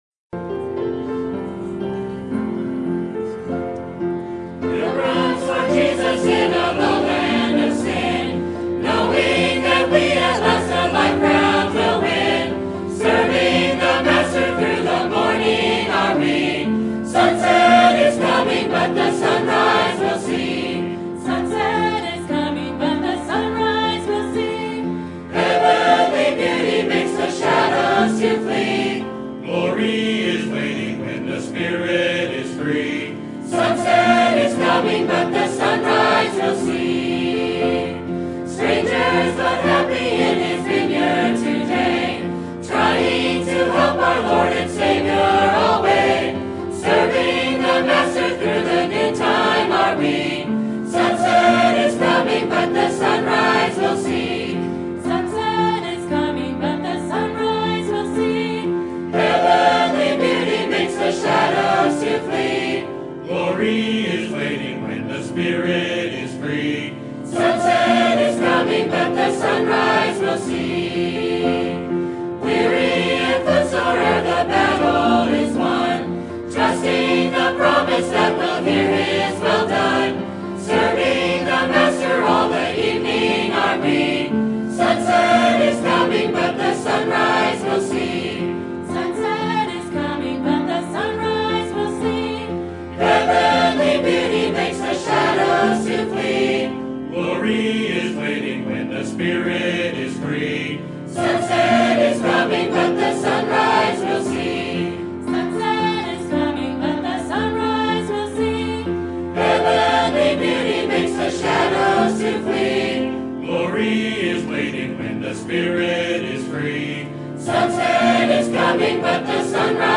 Sermon Topic: General Sermon Type: Service Sermon Audio: Sermon download: Download (25.36 MB) Sermon Tags: 1 Corinthians Faithful Strong Paul